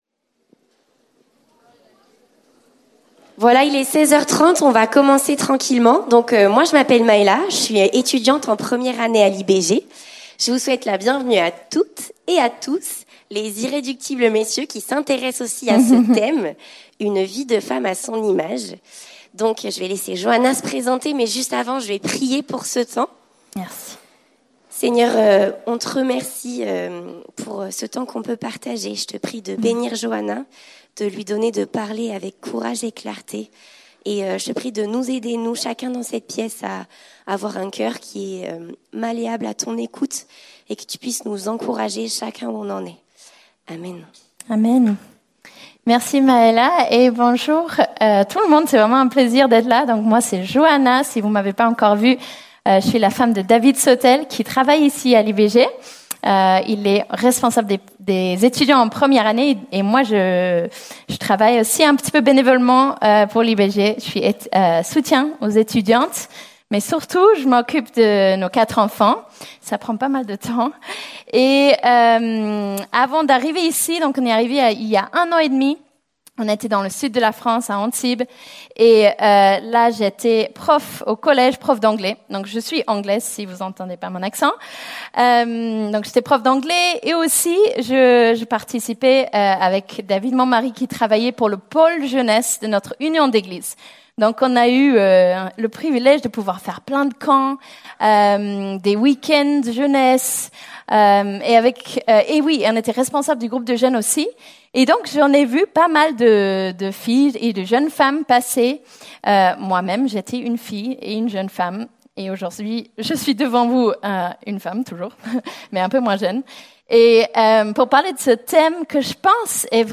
Ateliers Pâques 2025, Vivant